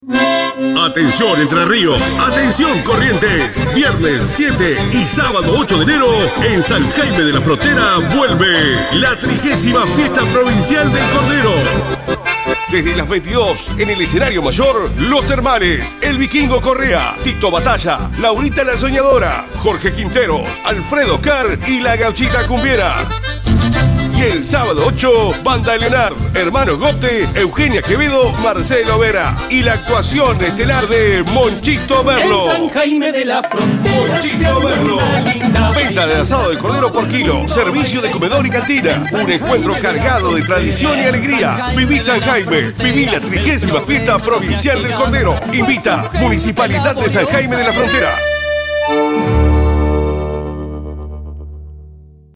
SPOT FIESTA DEL CORDERO 2022
spotcordero.mp3